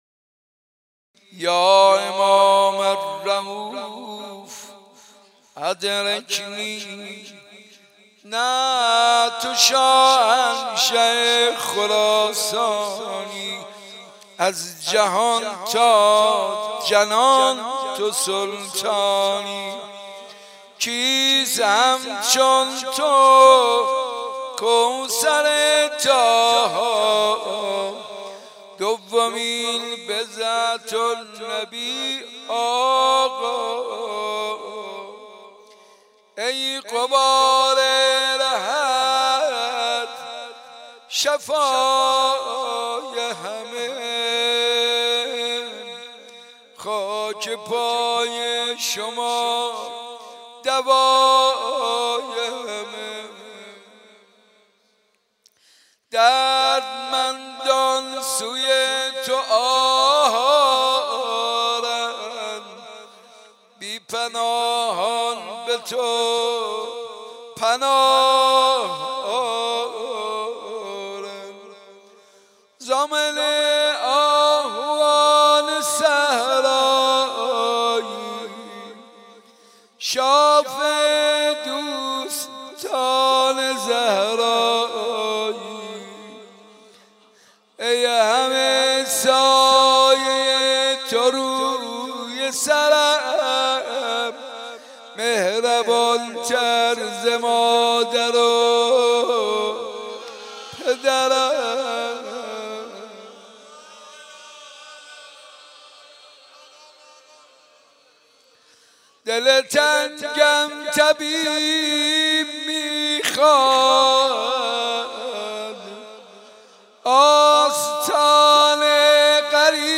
حسینیه صنف لباس فروشان
مداحی